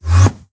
sounds / mob / endermen / portal2.ogg